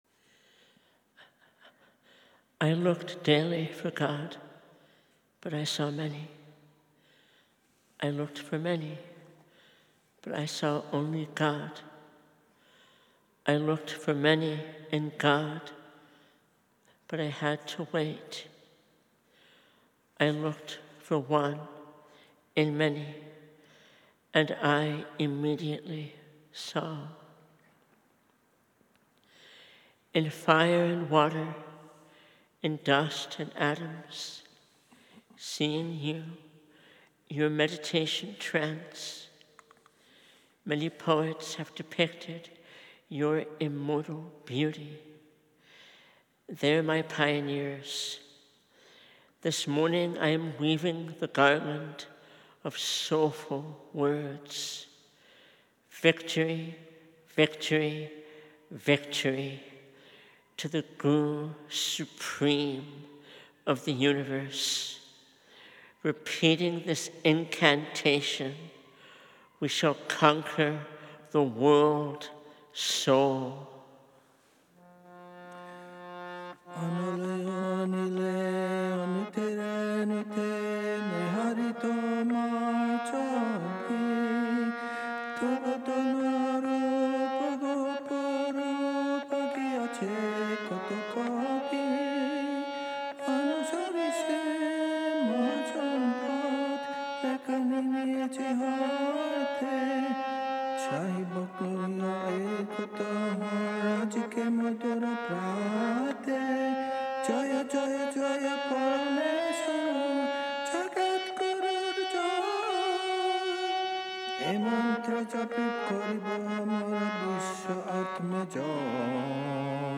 Transcendence-Perfection 50th Anniversary – Recital | Radio Sri Chinmoy
Location: Aspiration Ground, New York